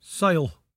[sY-al]